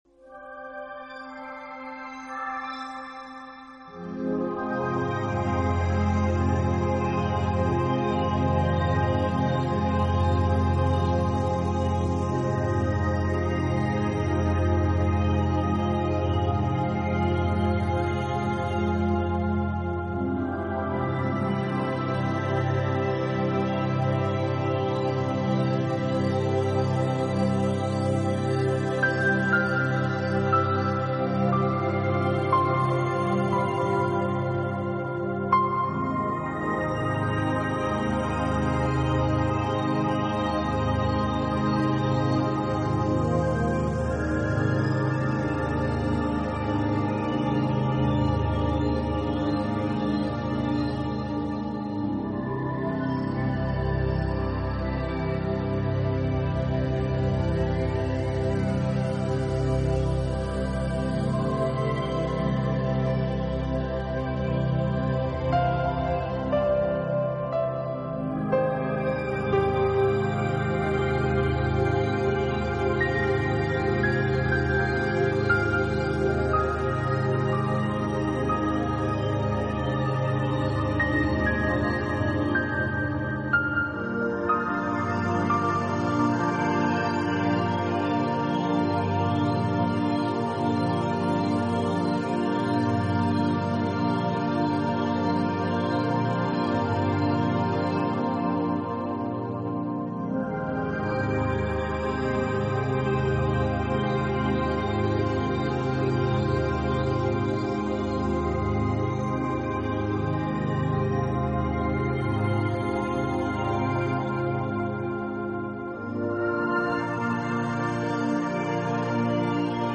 新世纪纯音乐
他的音乐跨度较大，从Ambient(环境)到Healing(有治疗功用)的众多音乐风格。